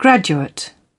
Transcription and pronunciation of the word "graduate" in British and American variants.